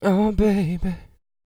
TB SING 306.wav